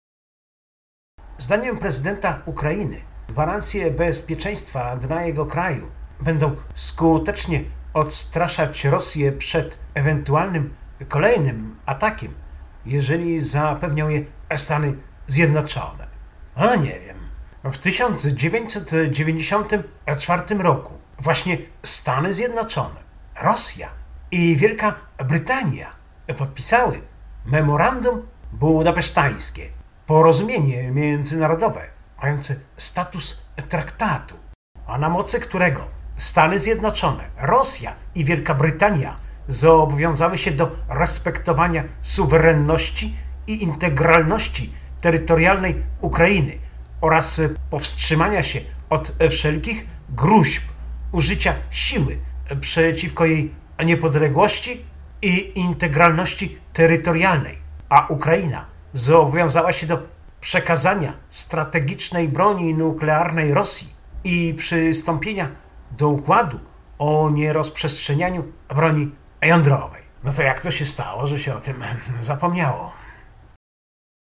W Radiu Maria od początku pełnoskalowego konfliktu w Ukrainie, w samo południe nadajemy audycję „Pół godziny dla Ukrainy”. Stałym elementem jest hymn Ukrainy, gdzie w tle słychać odgłosy walk i informacje z frontu.